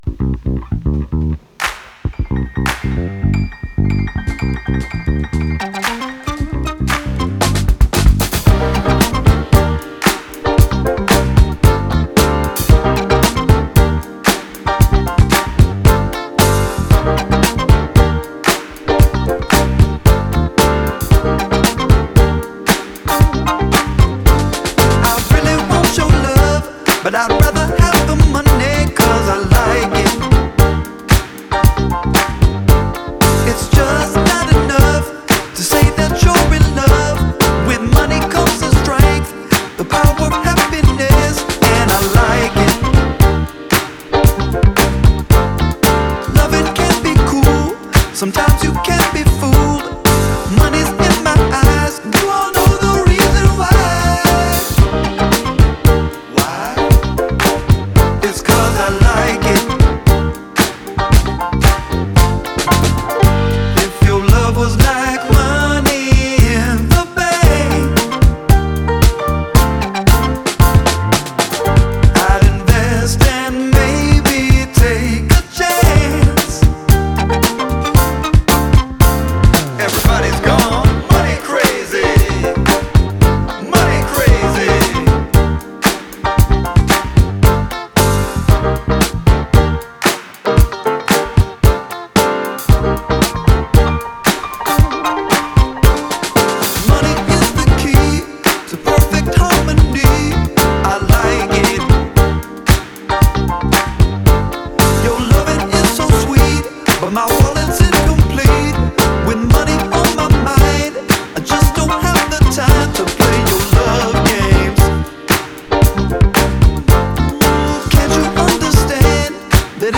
ジャンル(スタイル) SOUL / FUNK / DISCO